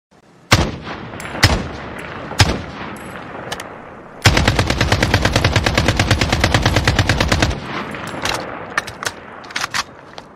Ak 47 Gun Sound Effect Free Download
Ak 47 Gun